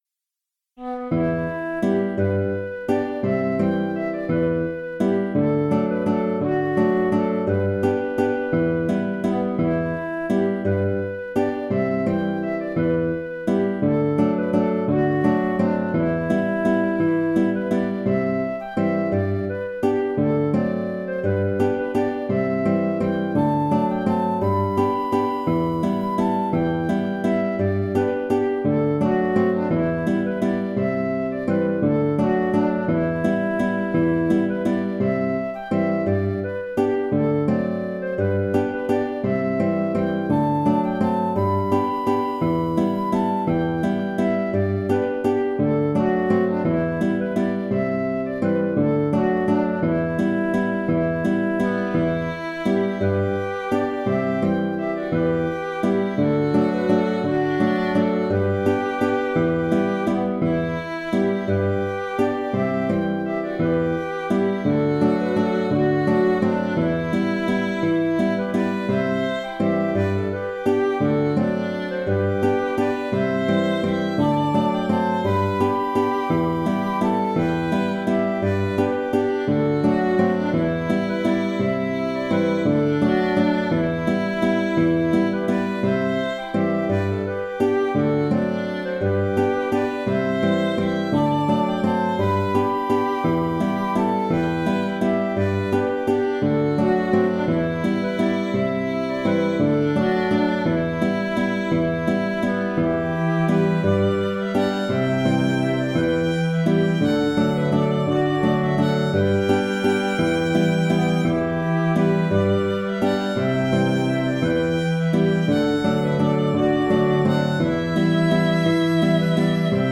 Valse écossaise
Je propose trois contrechants à alterner au choix des interprètes. Le troisième est proche du premier avec un peu plus de graves pour donner plus de profondeur. Le second étant beaucoup dans les aiguës, il est préférable de le tempérer, en volume d’abord, et en ajoutant éventuellement des basses, ce que j’ai fait avec le fichier audio.